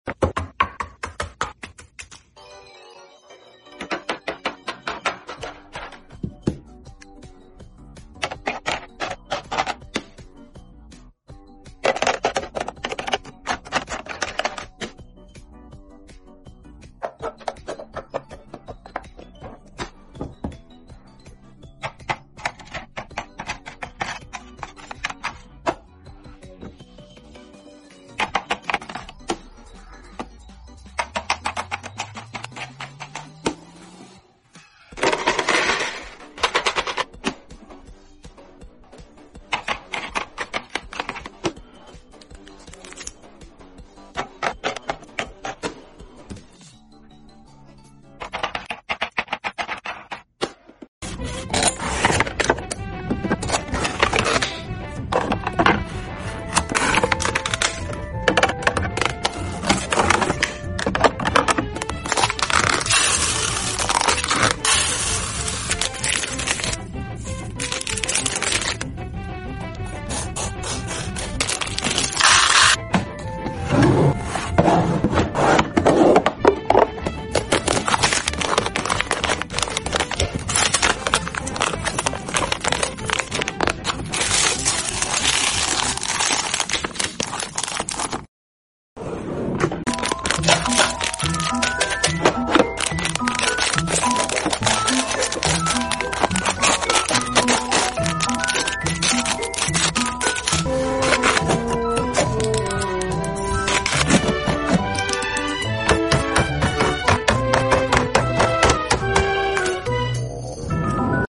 Fall Home Restock Asmr ♡ Sound Effects Free Download